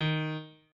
piano3_38.ogg